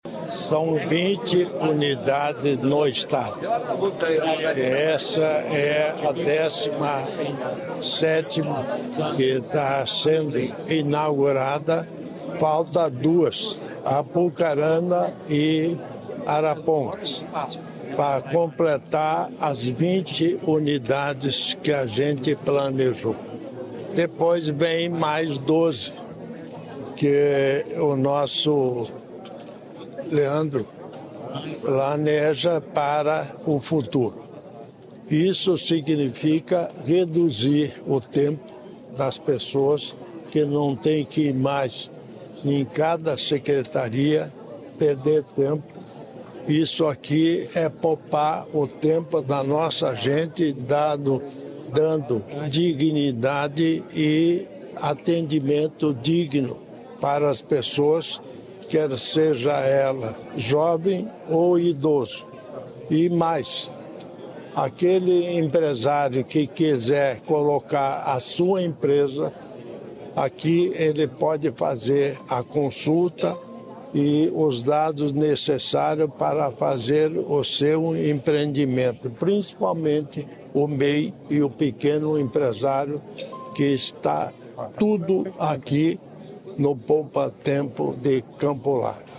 Sonora do governador em exercício, Darci Piana, sobre o novo Poupatempo em Campo Largo